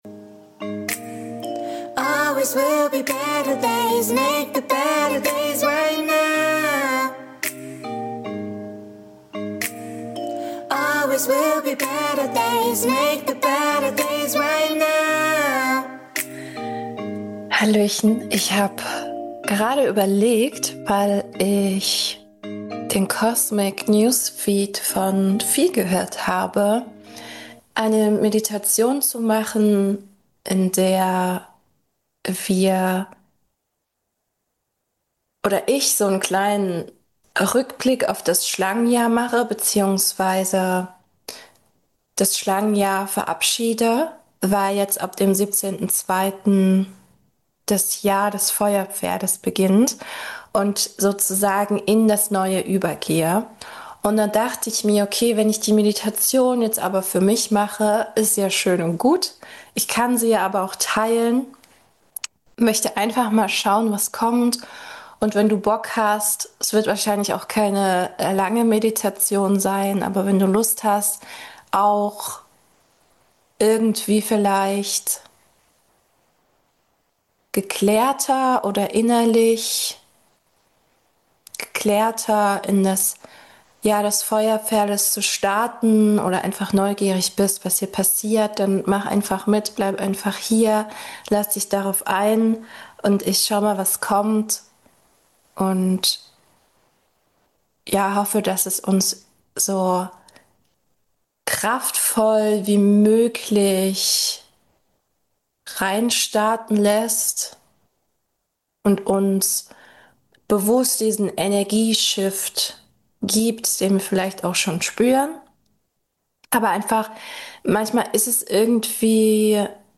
Ich möchte dich heute auf eine visuelle Reise mit Sounderlebnis mitnehmen. Eine Reise in der Wir uns in Dankbarkeit von der Schlange verabschieden und die Energie des Pferdes einsaugen.
Ich habe versucht durch die Sounds ein Erlebnis zu schaffen, was es dir noch einfacher macht tiefer in die Visualisierung einzutauchen.